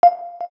KEYTONE1_4.wav